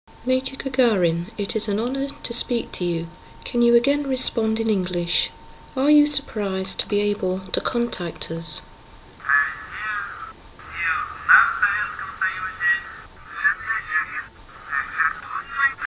Obviously, I have no way of proving that this is Yuri Gagarin, but the voice does sound remarkably similar.